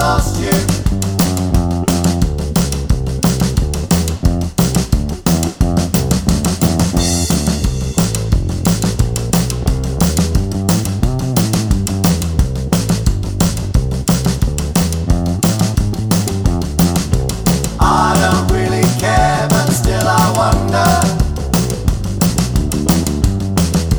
no Backing Vocals Rock 'n' Roll 3:54 Buy £1.50